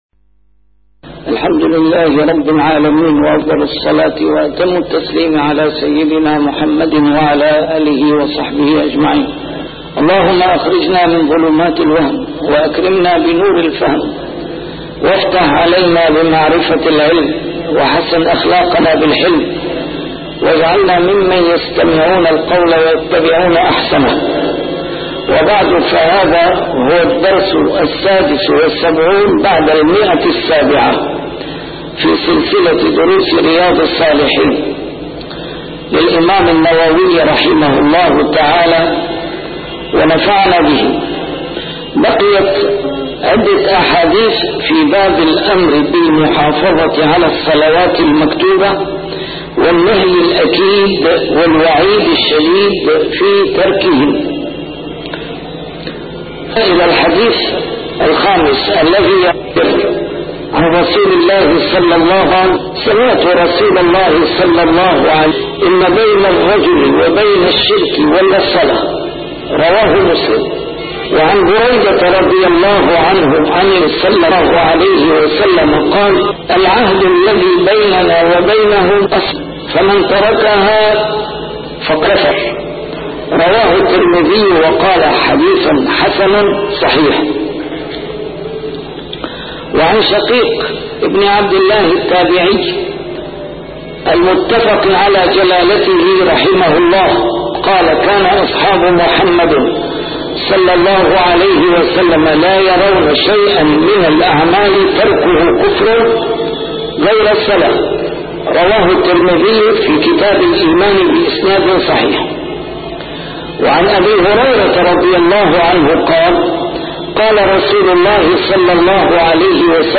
A MARTYR SCHOLAR: IMAM MUHAMMAD SAEED RAMADAN AL-BOUTI - الدروس العلمية - شرح كتاب رياض الصالحين - 776- شرح رياض الصالحين: المحافظة على الصلوات المكتوبات